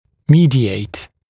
[‘miːdɪeɪt][‘ми:диэйт]быть промежуточным звеном, передавать